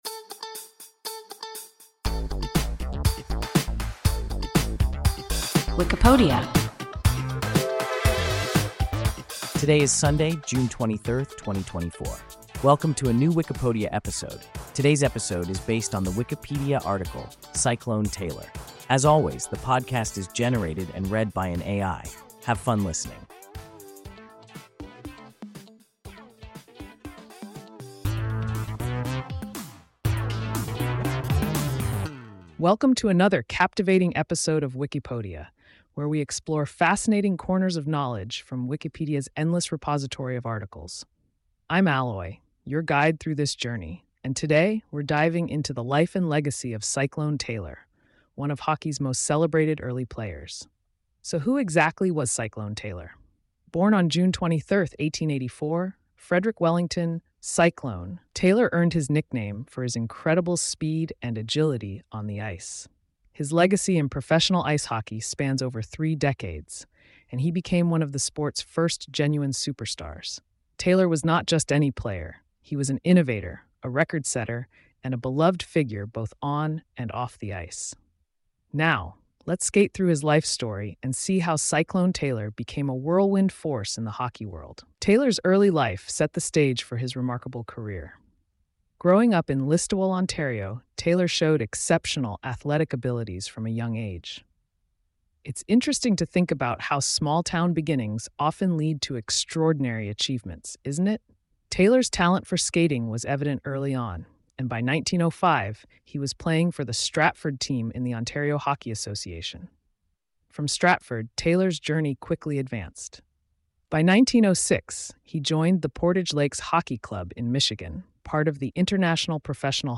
Cyclone Taylor – WIKIPODIA – ein KI Podcast